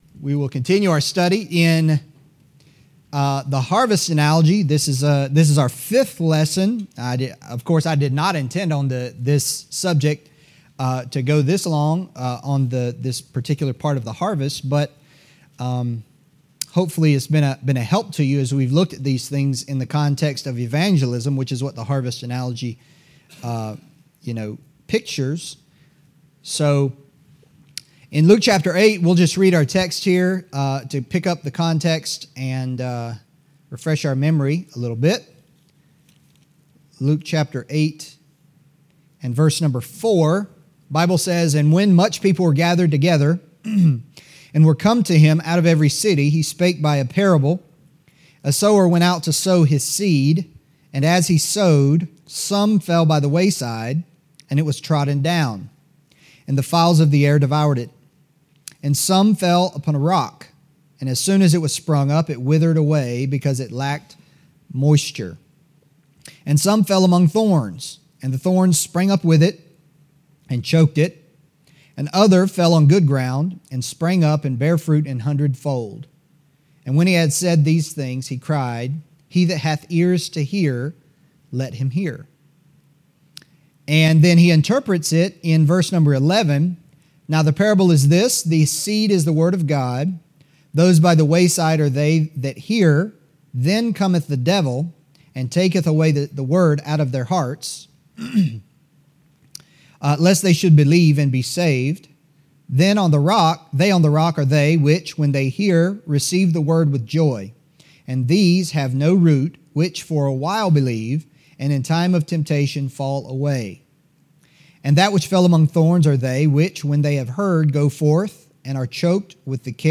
Adult Sunday Shool: School of Evangelism &middot